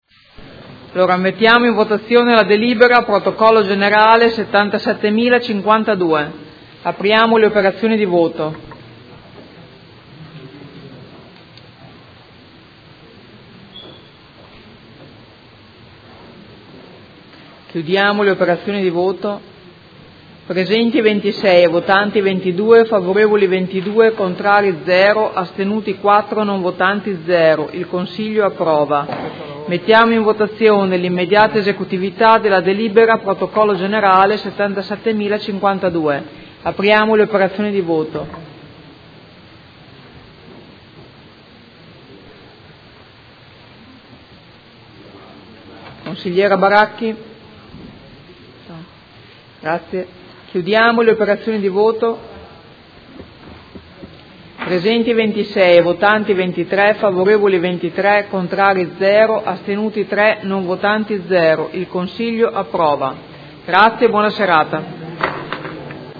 Seduta del 01/06/2017.